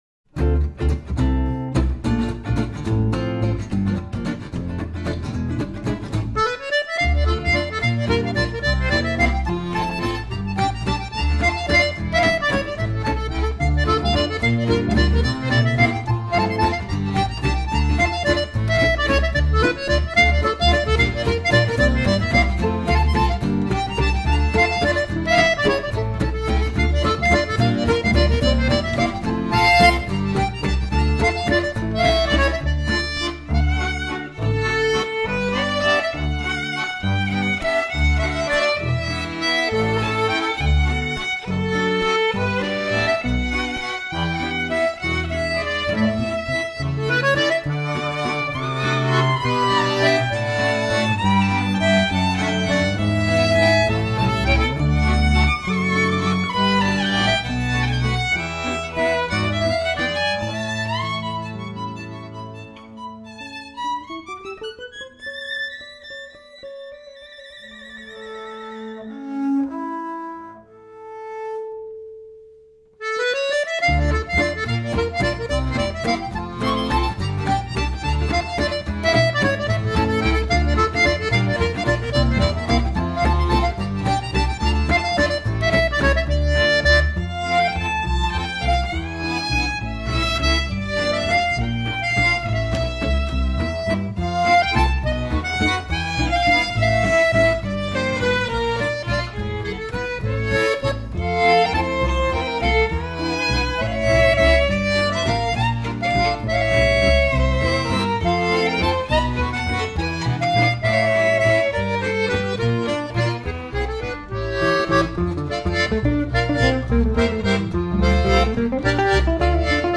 violon, voix, idées fixes aux pieds
alto, voix, guitare sommaire
accordéon, hackbrett, voix, cuillères ashuprazes
contrebasse, voix, paire de claques
guitare
batterie, tabla, târ et autres percussions